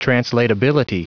Prononciation du mot translatability en anglais (fichier audio)